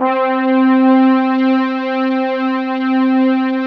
ORCHEST.C4-L.wav